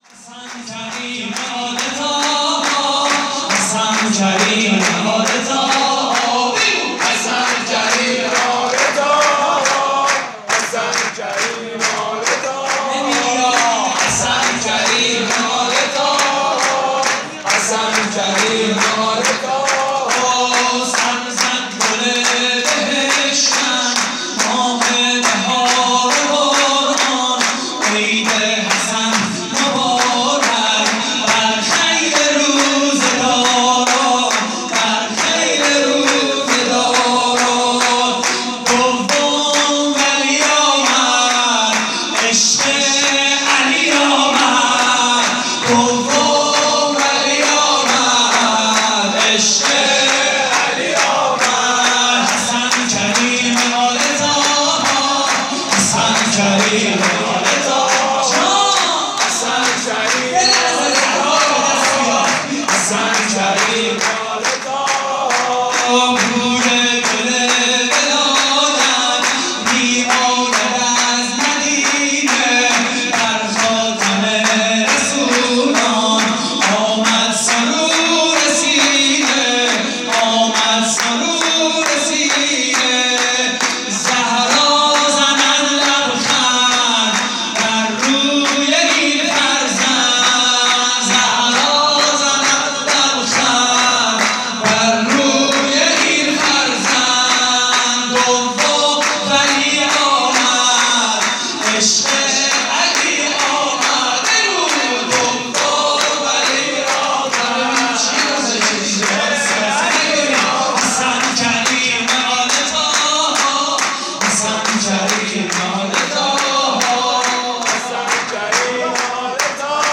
مراسم جشن ولادت امام حسن مجتبی (ع) / هیئت رایة الهدی – دانشگاه علوم پزشکی حضرت بقیة الله (عج)؛ 28 فروردین 1401
شور: سر زد گل بهشتم؛ پخش آنلاین |